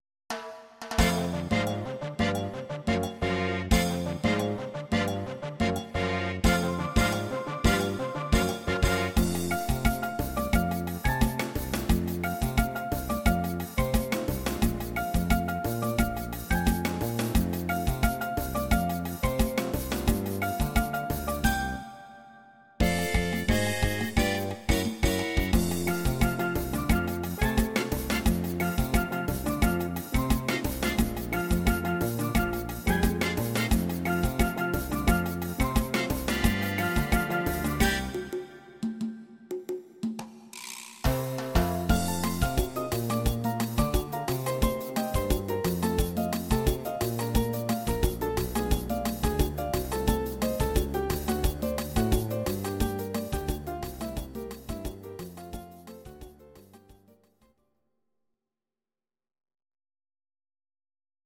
Audio Recordings based on Midi-files
Jazz/Big Band, Instrumental, Traditional/Folk